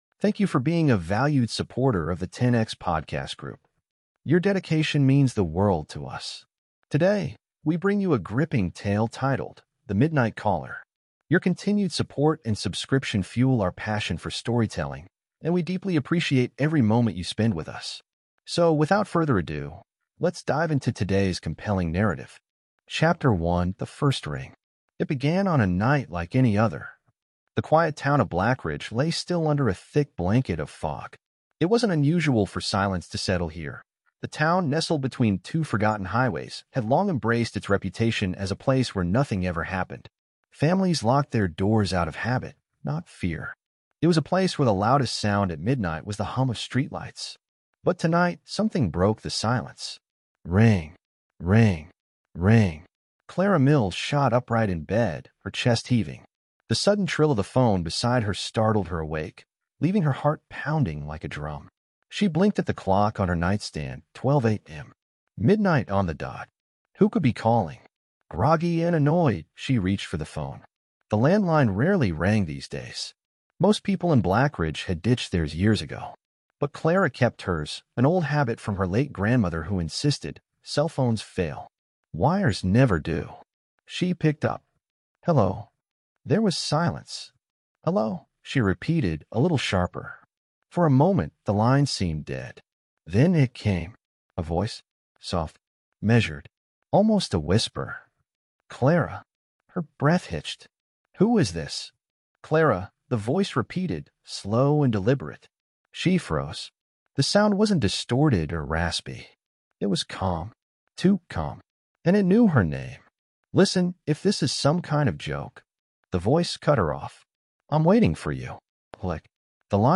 And will they survive long enough to stop it?Prepare for an edge-of-your-seat storytelling podcast brought to you by The Murder Tape Khronicles.